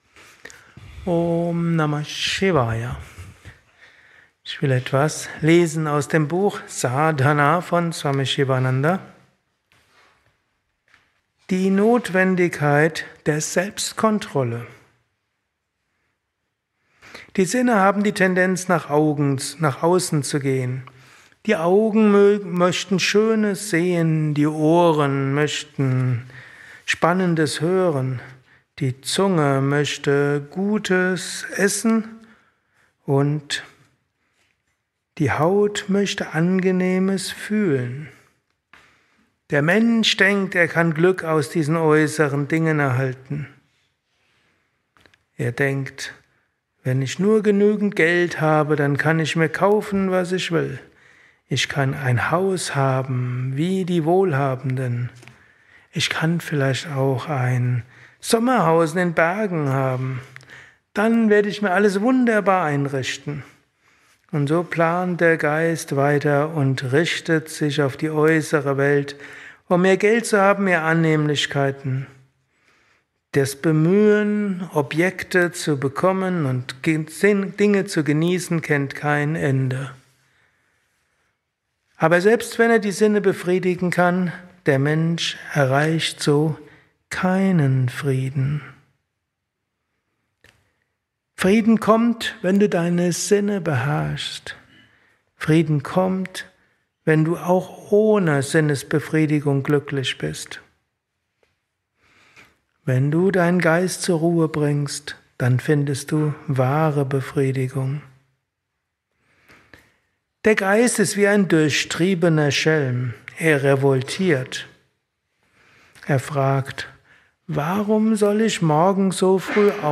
eines Satsangs gehalten nach einer Meditation im Yoga Vidya